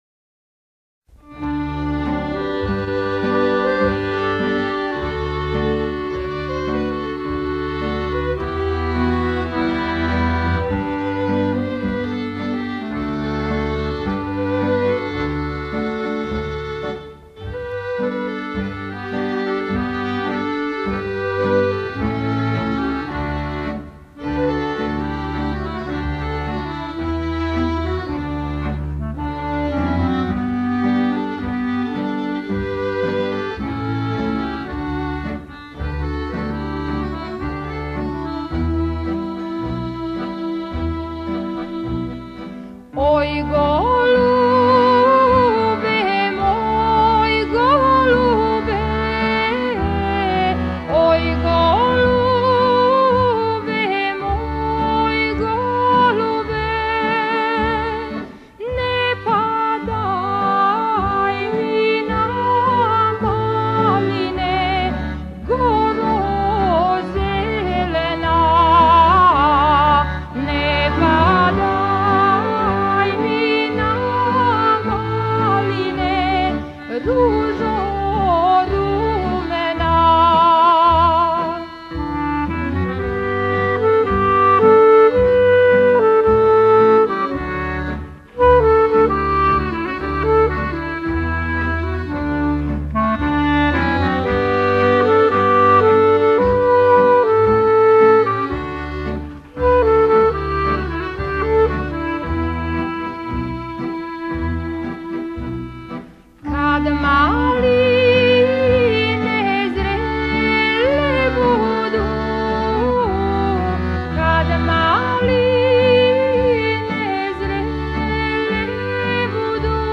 Напомена: Сватовска (девојачка) песма.